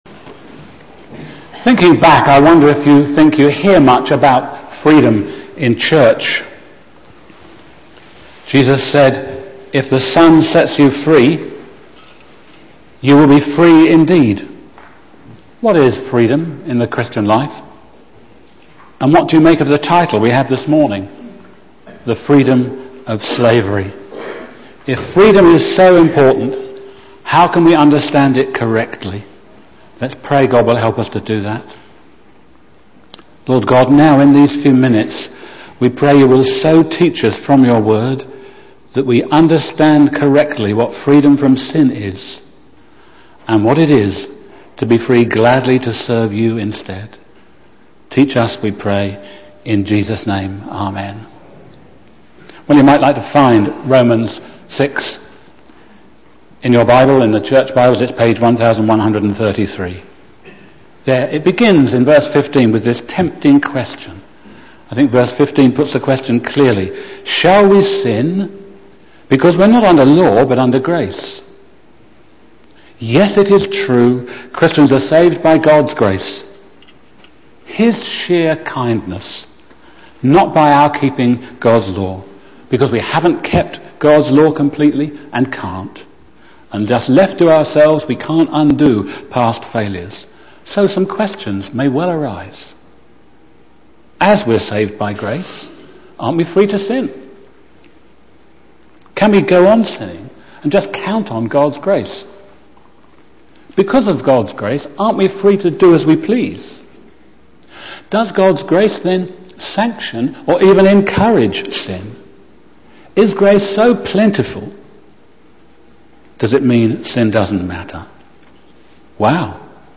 Bible Text: Romans 6:15-23 | Preacher